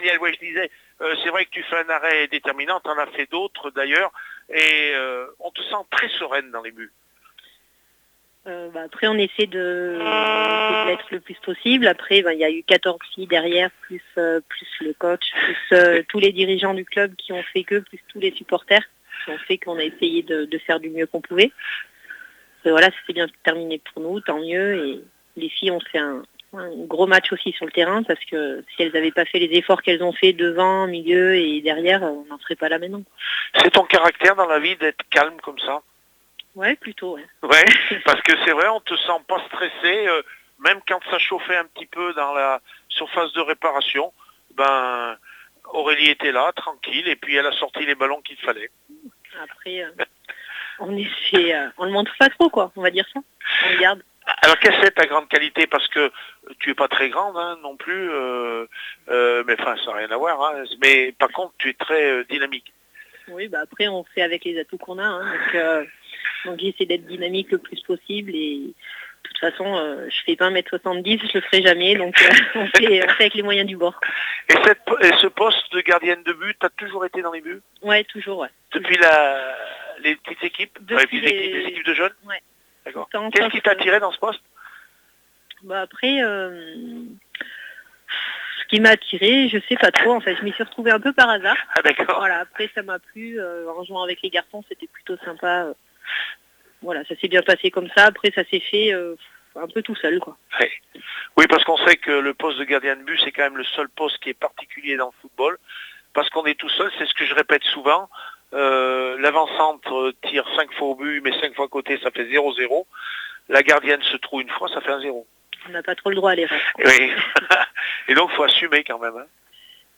REACTIONS